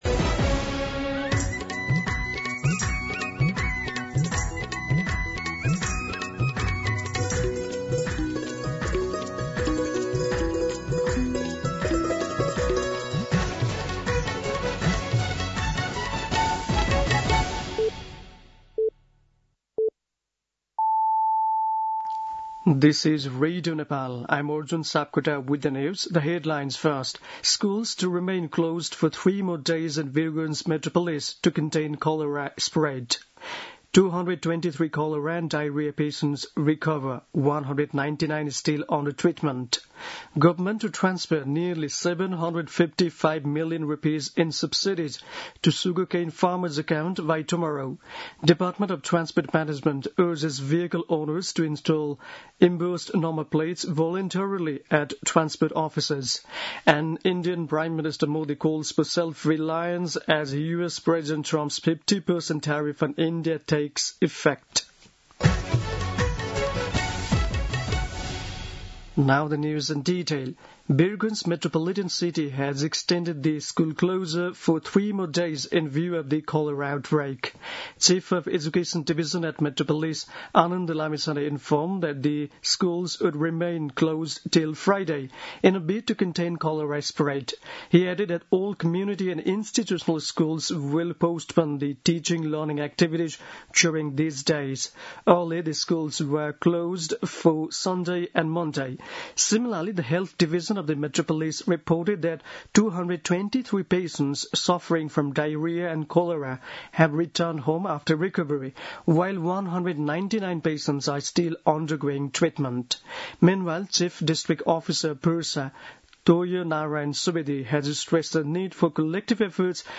दिउँसो २ बजेको अङ्ग्रेजी समाचार : ११ भदौ , २०८२